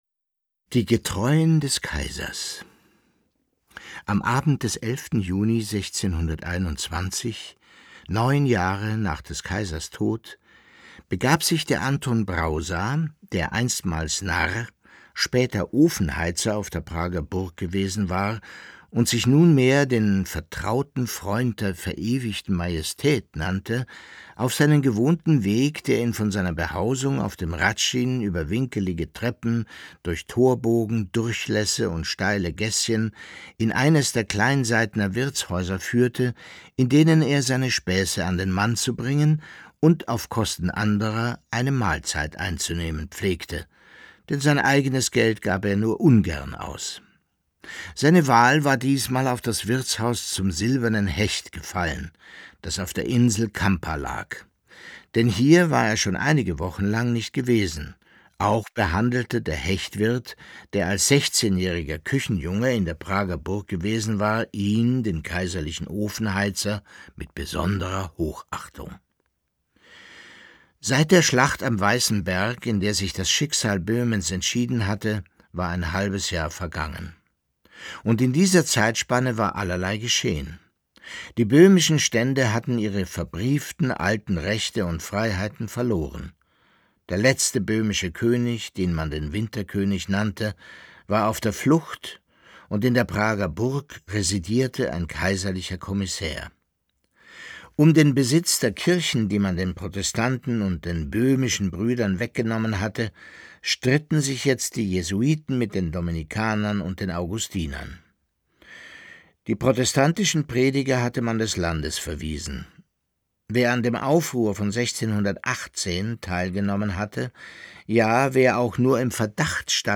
Leo Perutz: Nachts unter der steinernen Brücke (20/25) ~ Lesungen Podcast